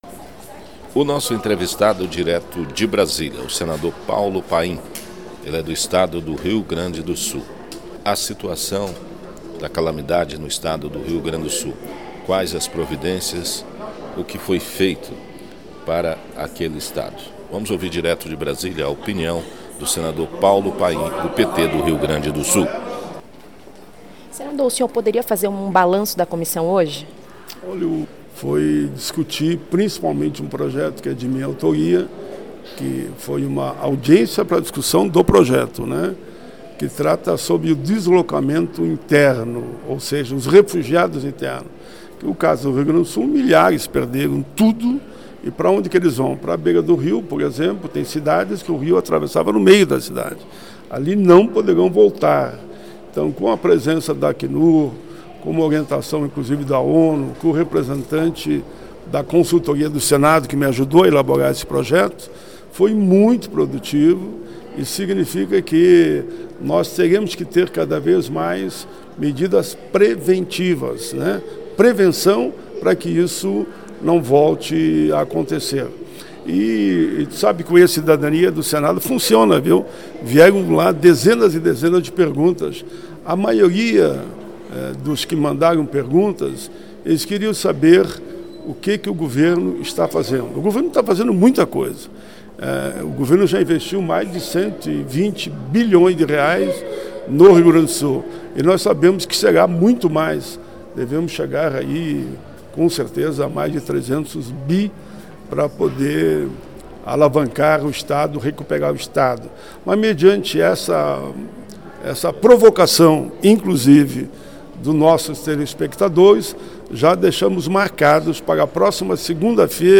O senador gaúcho Paulo Paim, da Comissão Temporária Externa para acompanhar as atividades relativas ao enfrentamento da calamidade que atingiu o Rio Grande do Sul, foi ouvido pelo nosso correspondente em Brasília